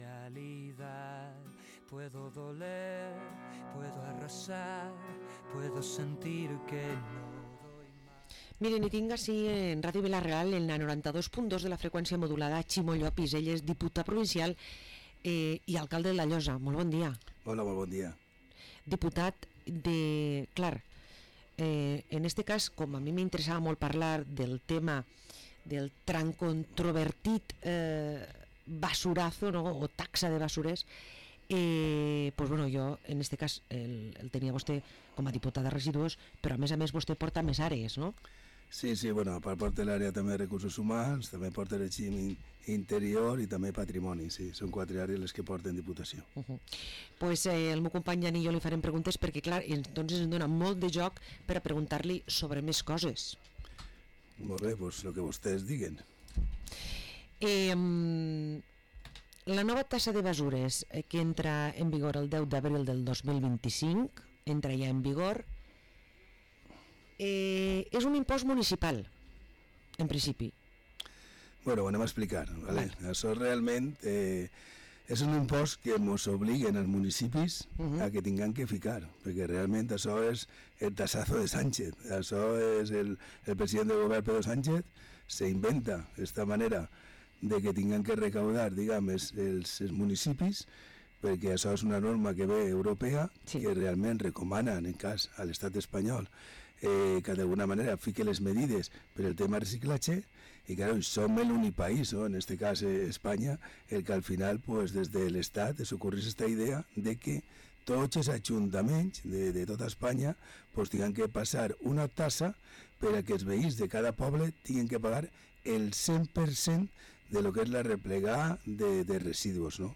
Parlem amb el diputat provincial i alcalde de La Llosa Ximo Llopis